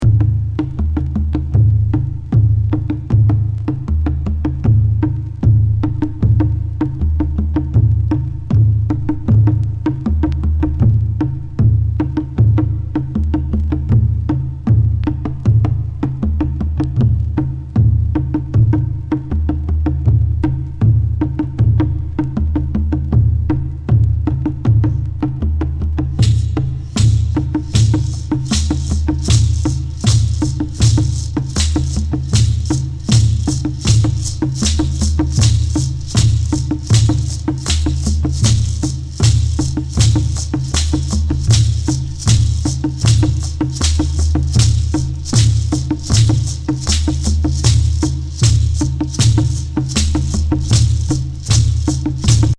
アフリカン～アボリジニー風音源。ミスティック・パーカッション